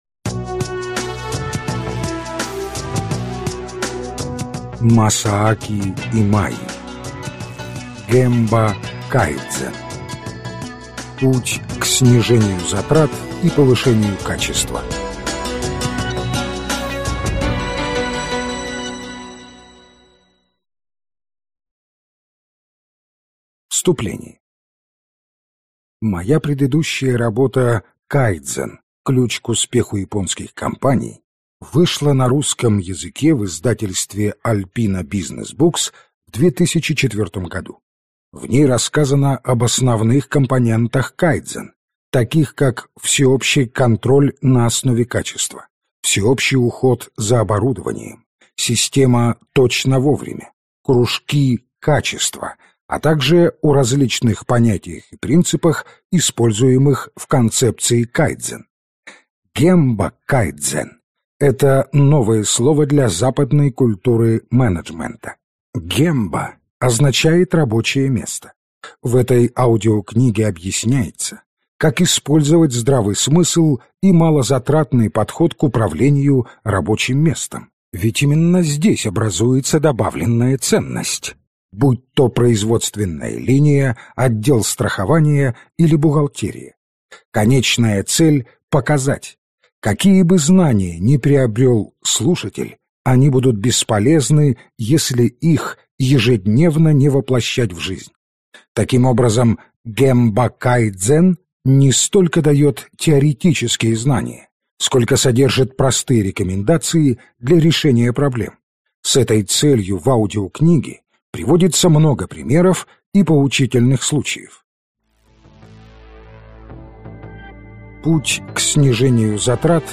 Аудиокнига Гемба кайдзен. Путь к снижению затрат и повышению качества | Библиотека аудиокниг